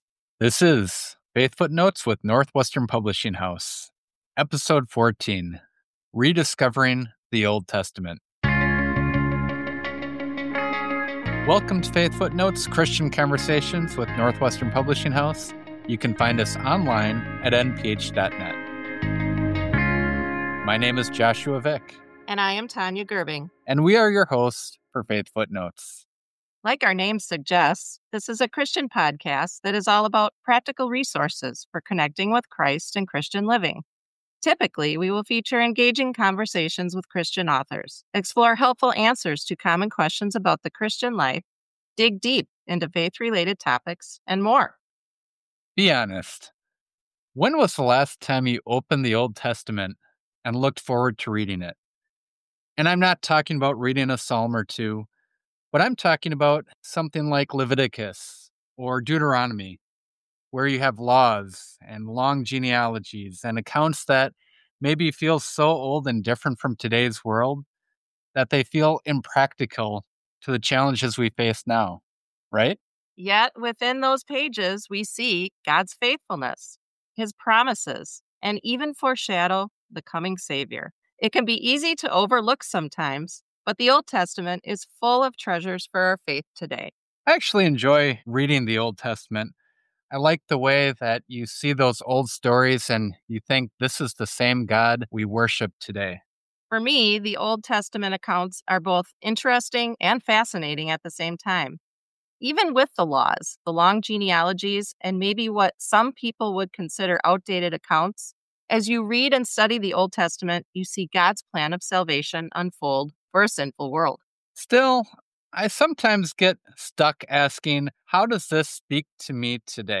Whether you’ve been hesitant to open the Old Testament or simply want to see it with fresh eyes, this conversation offers encouragement and perspective on why these ancient books are still vital for our faith today.